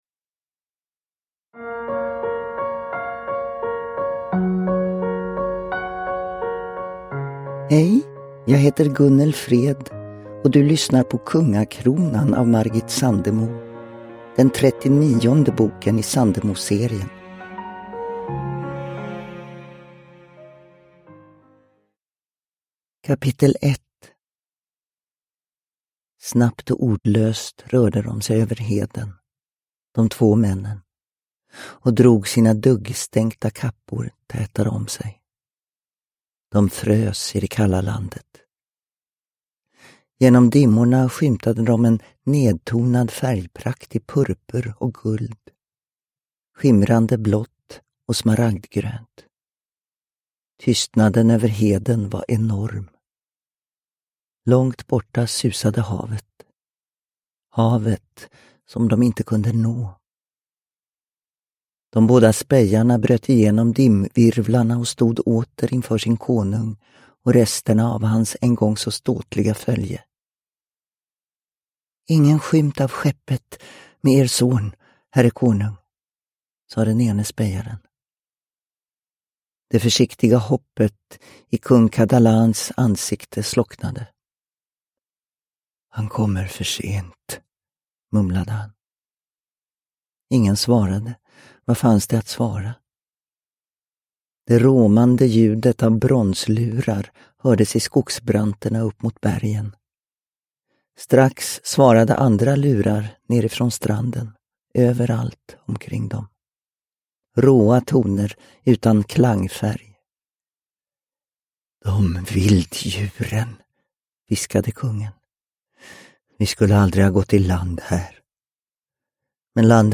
Kungakronan – Ljudbok